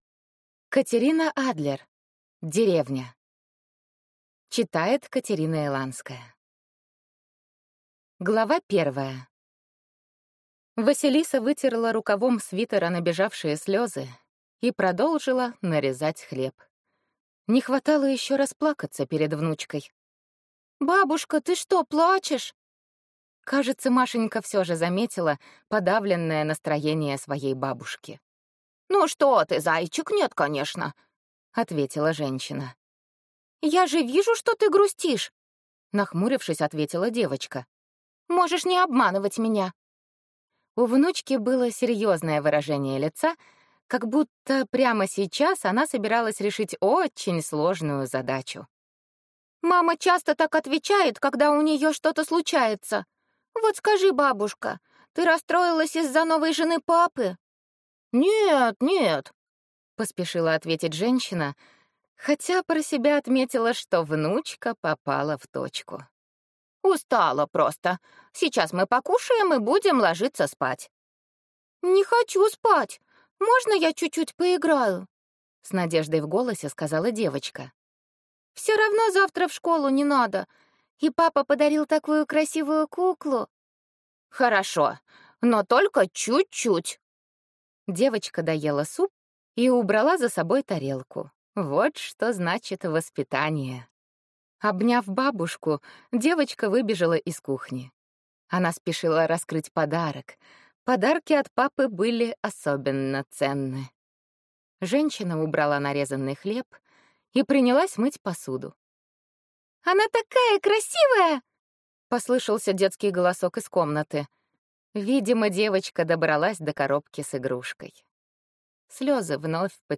Аудиокнига Деревня | Библиотека аудиокниг